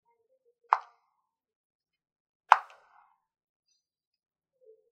Arrugando papeles
Grabación sonora en la que se capta el sonido de alguien golpeando con una paleta una pelota de ping-pong
Sonidos: Acciones humanas